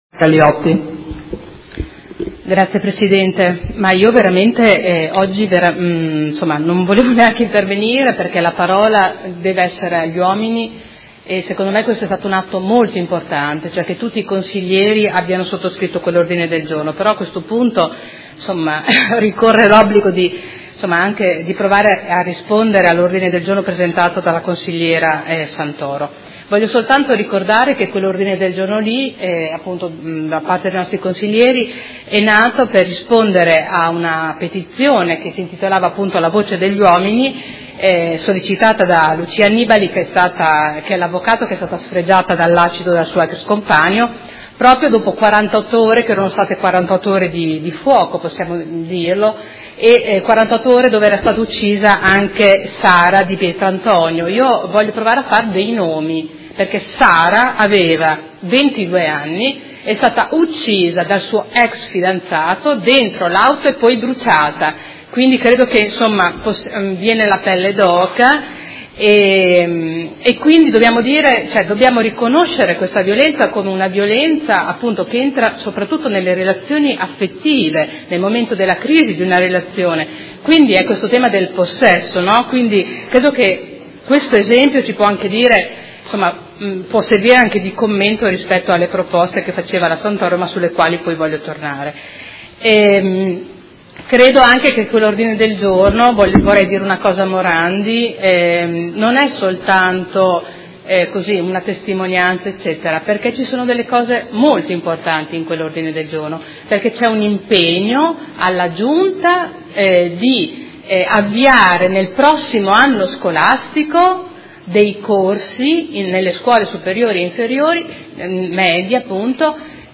Caterina Liotti — Sito Audio Consiglio Comunale
Dibattito